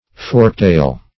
Search Result for " forktail" : The Collaborative International Dictionary of English v.0.48: Forktail \Fork"tail`\, n. (Zool.) (a) One of several Asiatic and East Indian passerine birds, belonging to Enucurus , and allied genera.